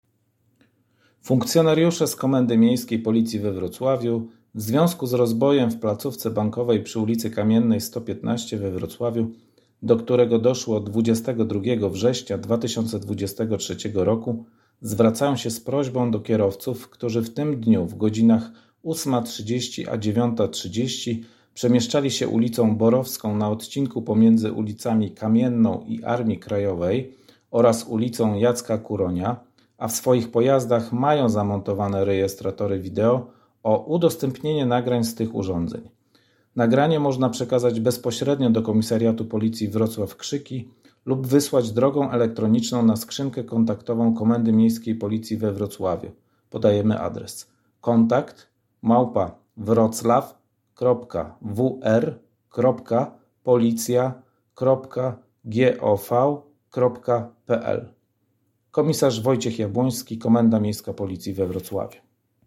Nagranie - komunikat głosowy - plik mp3
komunikatglosowy.mp3